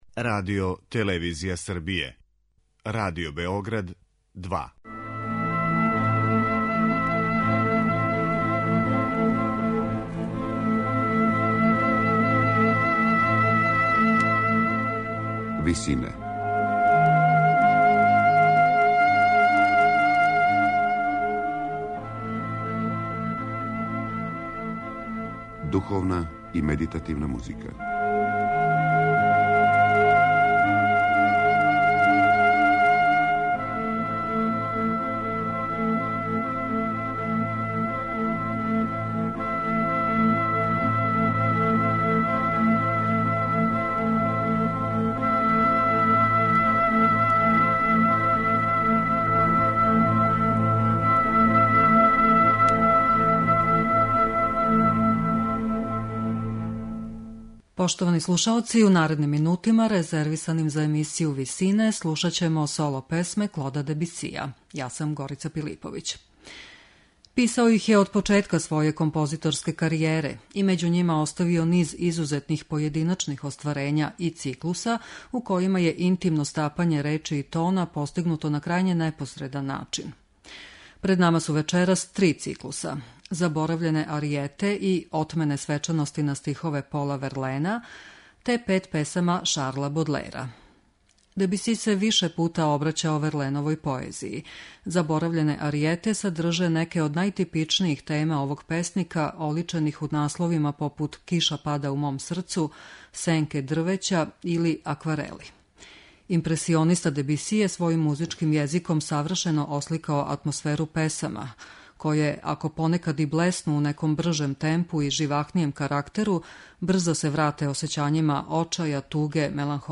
Соло песме Клода Дебисија